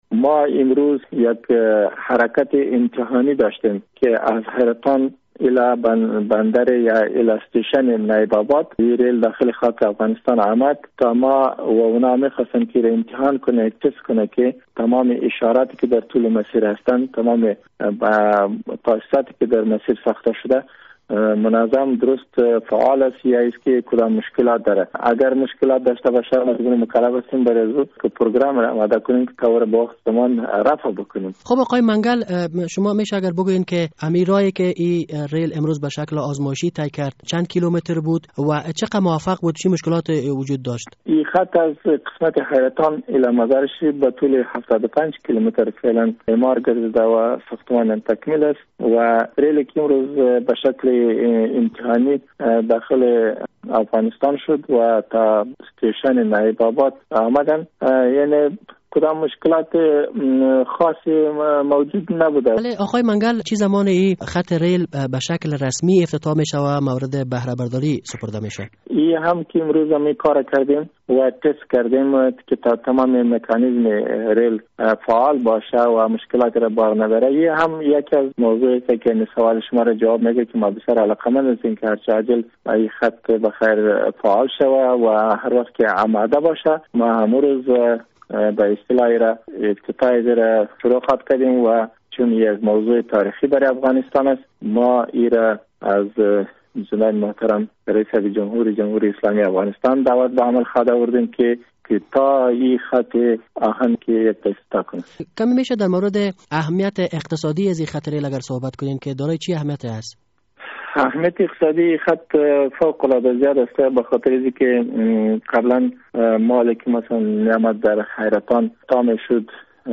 مصاحبه: امروز نخستین ریل در افغانستان به حرکت درآمد